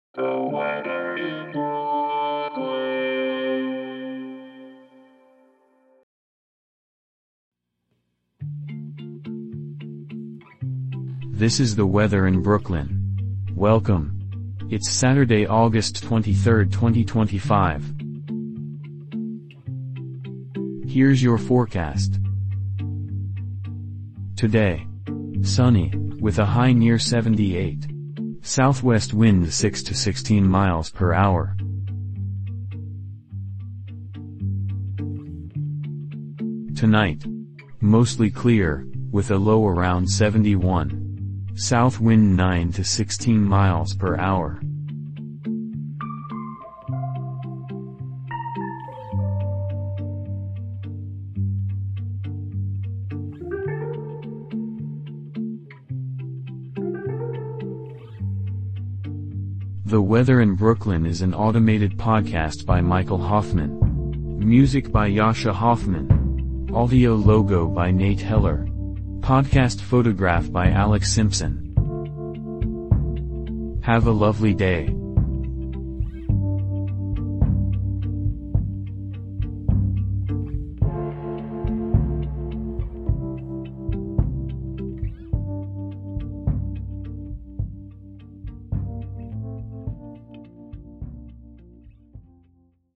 is generated automatically